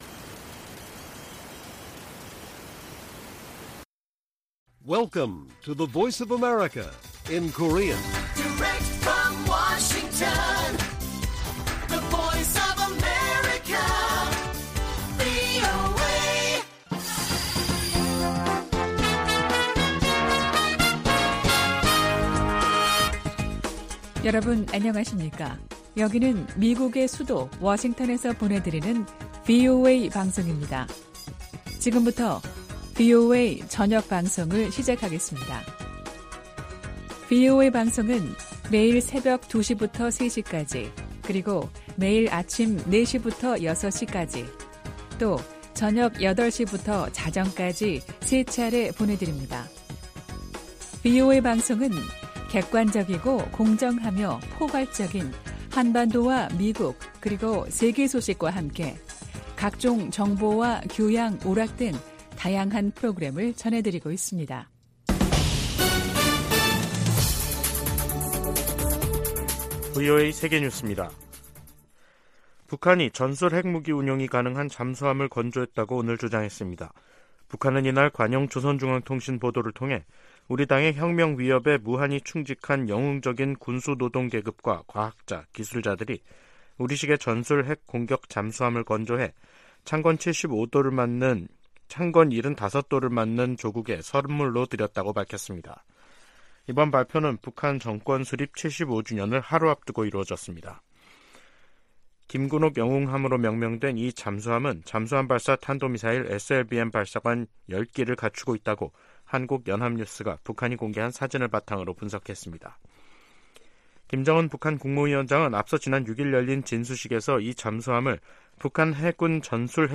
VOA 한국어 간판 뉴스 프로그램 '뉴스 투데이', 2023년 9월 8일 1부 방송입니다. 북한이 수중에서 핵 공격이 가능한 첫 전술 핵공격 잠수함인 '김군옥 영웅함'을 건조했다고 밝혔습니다. 인도네시아에서 열린 동아시아정상회의(EAS)에 참석한 카멀라 해리스 미국 부통령이 북한의 위협적 행동을 강력히 규탄했습니다. 러시아와 무기 거래를 하려는 북한은 '매우 위험한 게임'을 하는 것이라고 미국 상원 외교위원장이 지적했습니다.